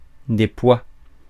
Ääntäminen
France (Paris): IPA: [ɛ̃ pwa]